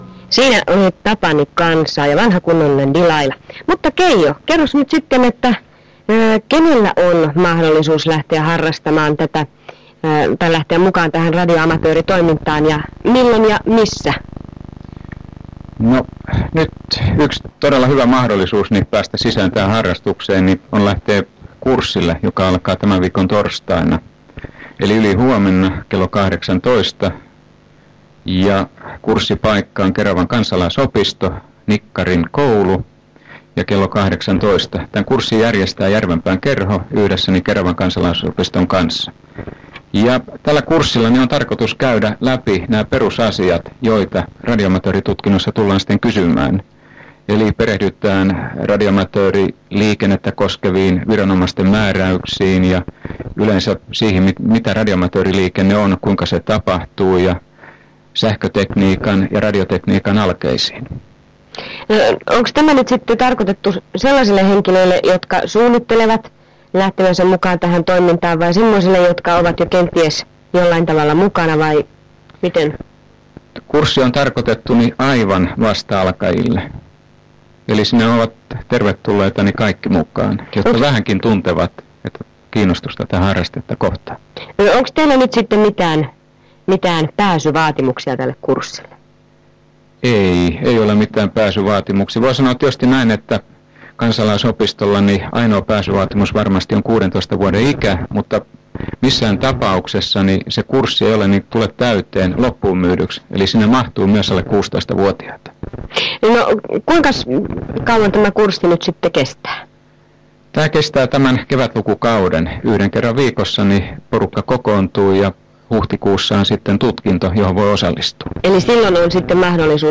Radio Etelän Ääni Keravan paikallisradiossa 1992